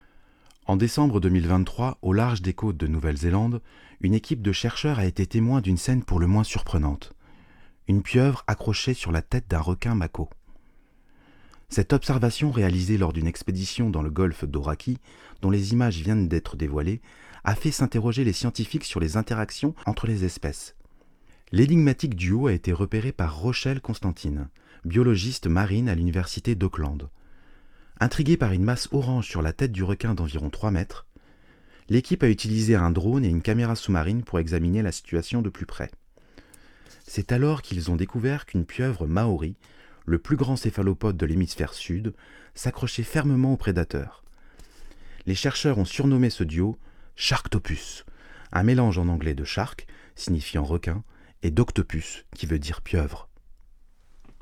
Voix off
- Baryton